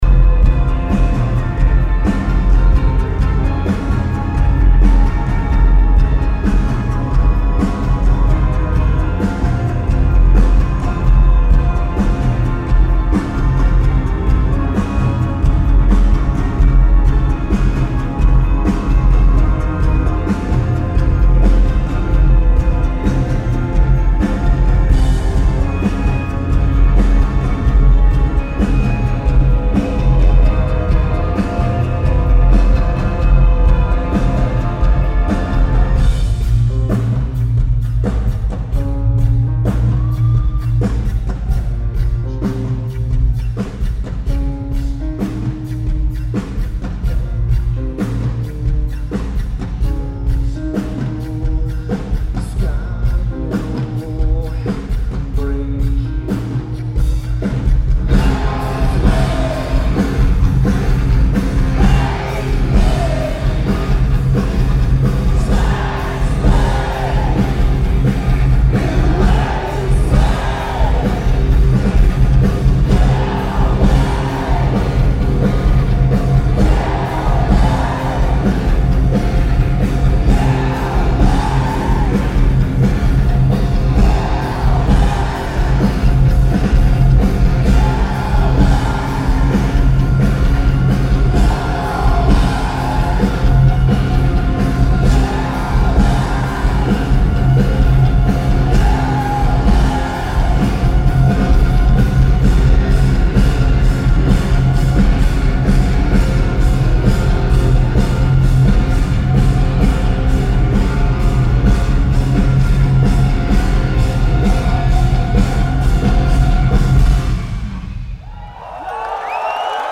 Forum
Copenhagen Denmark
Lineage: Audio - AUD (Sony PCM-M10)
Notes: This is an average recording.